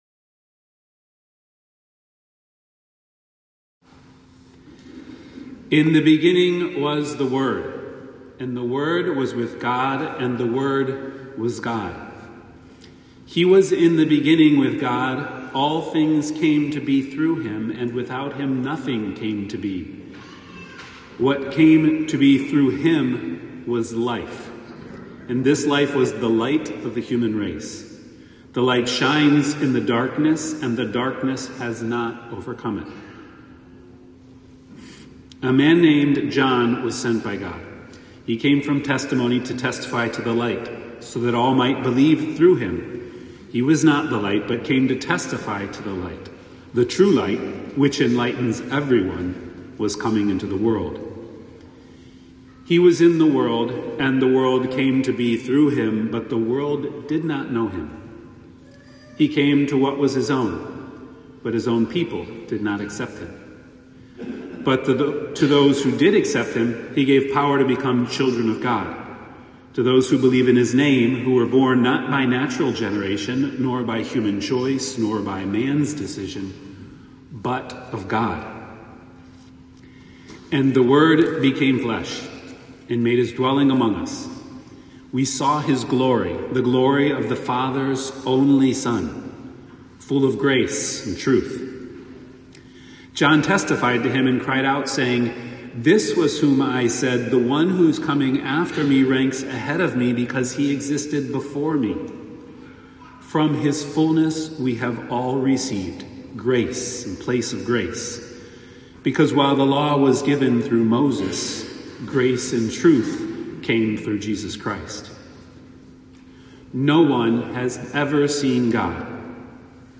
homily for December 25th, 2025.